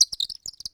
Audio / SFX / Characters / Voices / PhoenixChick